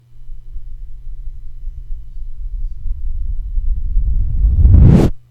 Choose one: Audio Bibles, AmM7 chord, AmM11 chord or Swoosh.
Swoosh